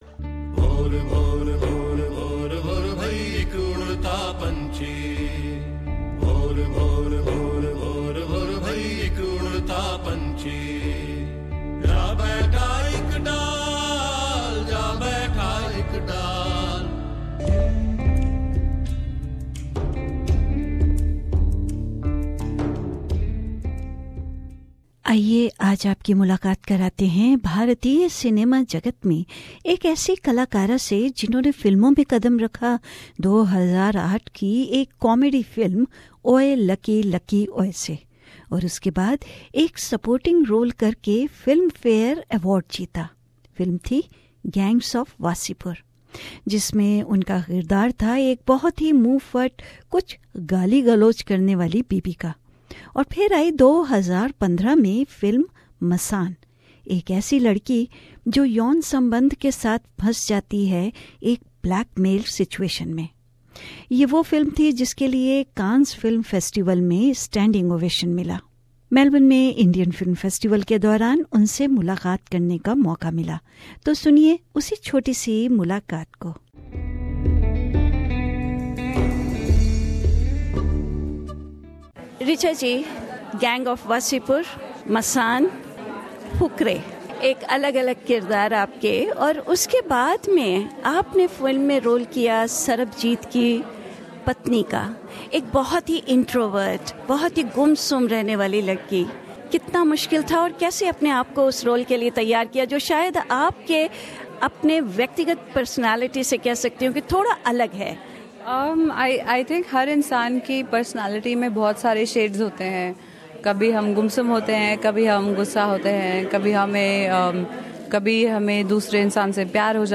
मेलबर्न में फिन्म फेस्टीवल के दौरान रिचा चड्ढ़ा आयी।